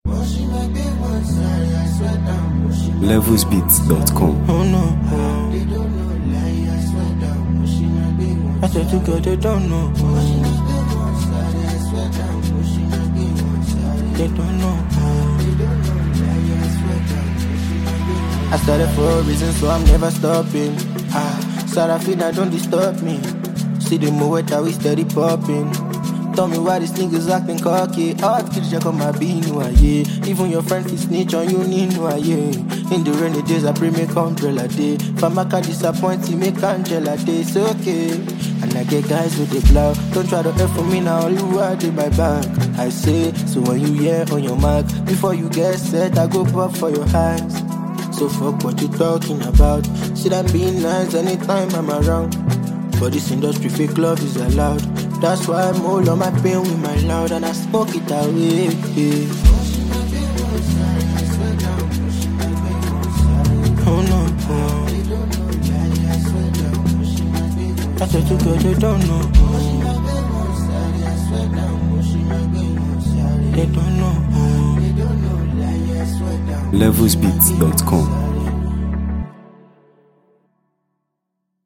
top-tier Afrobeat and soulful Nigerian music.